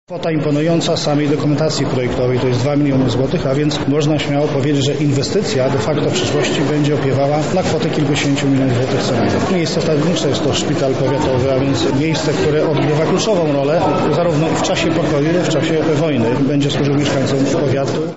O inwestycji dla powiatu Łęczyńskiego mówi Daniel Słowik, starosta.